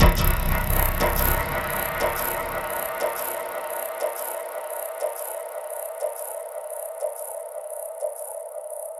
Processed Hits 24.wav